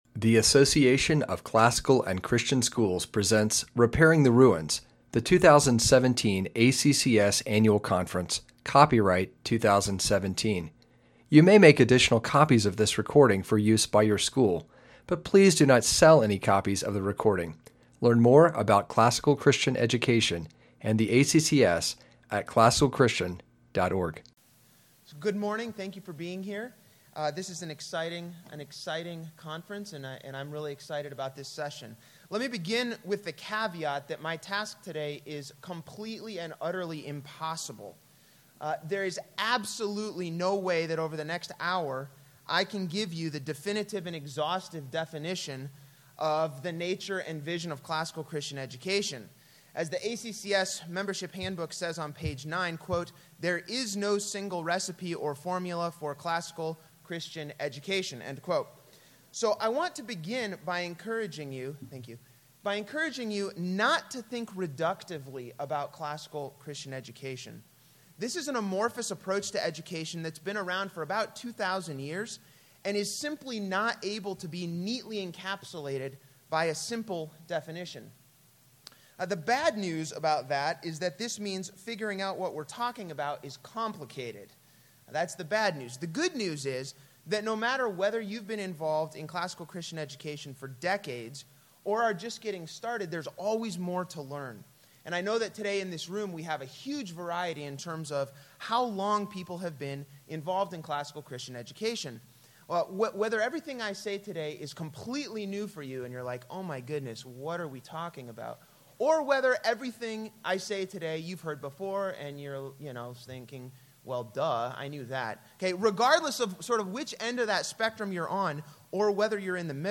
2017 Foundations Talk | 2:11:32 | All Grade Levels
Jan 9, 2019 | All Grade Levels, Conference Talks, Foundations Talk, Library, Media_Audio | 0 comments